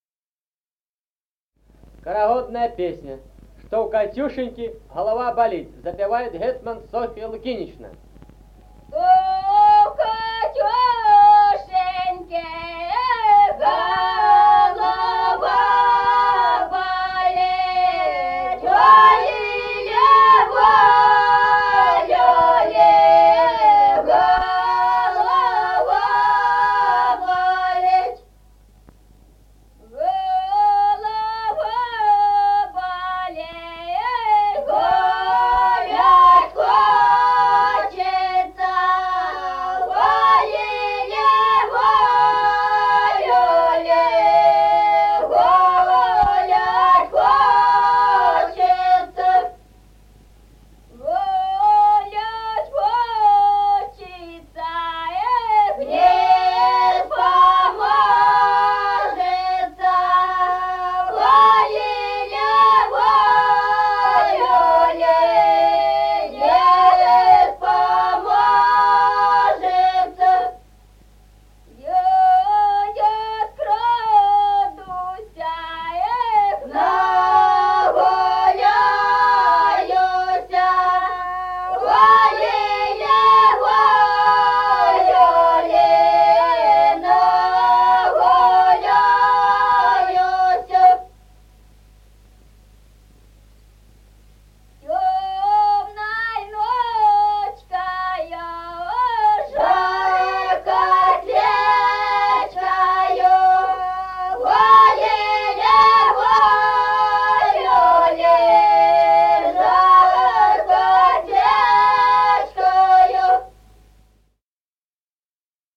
Музыкальный фольклор села Мишковка «Что у Катюшеньки», хороводная.